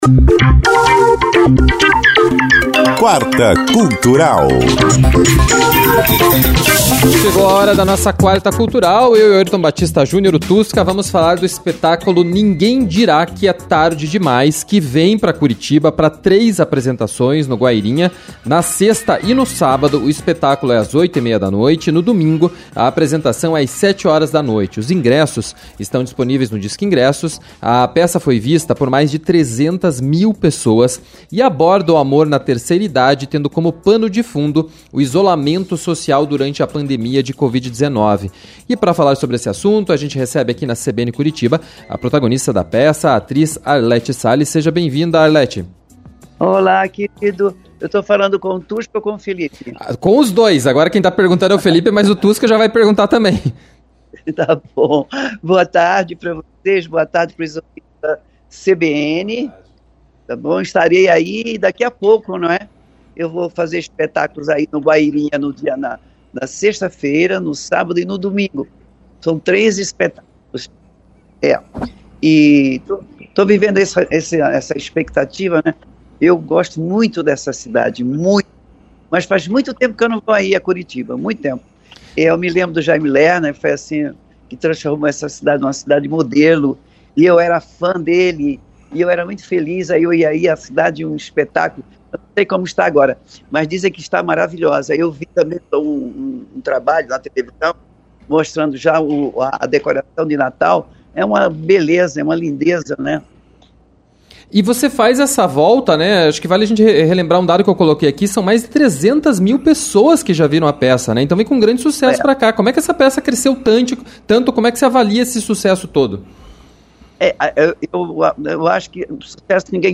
conversaram com a protagonista da peça, a atriz Arlete Salles.